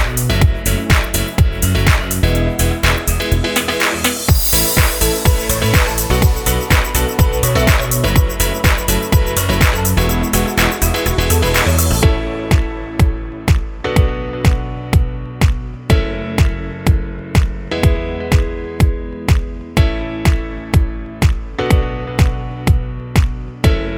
no Backing Vocals Pop (2010s) 2:39 Buy £1.50